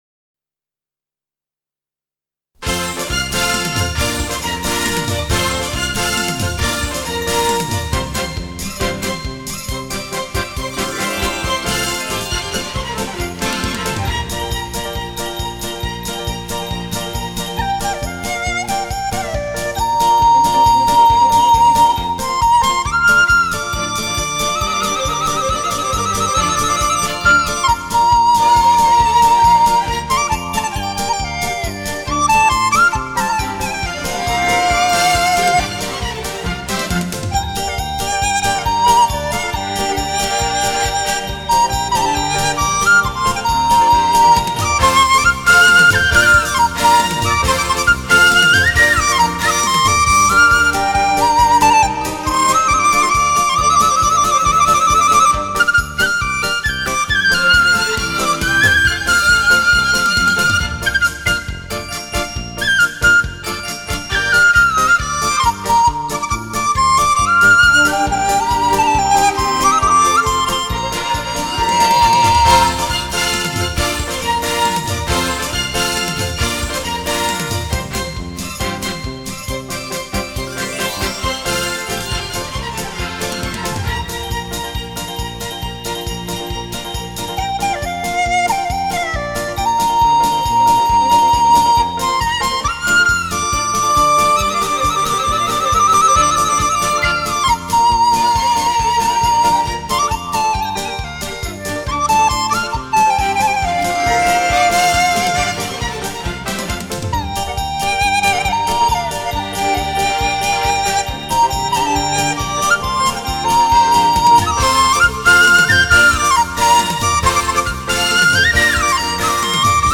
笛子独奏